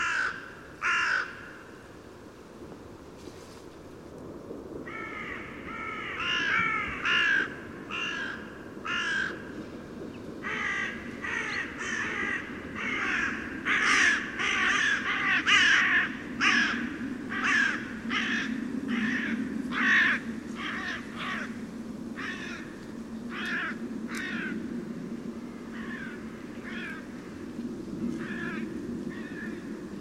corneille-noire.mp3